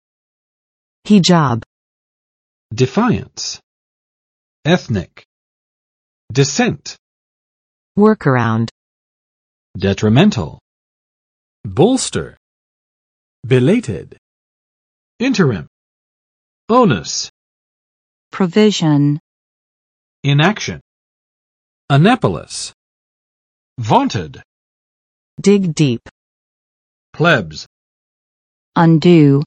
[ˈhɪdʒɑb] n.穆斯林妇女戴的面纱或头巾
hijab.mp3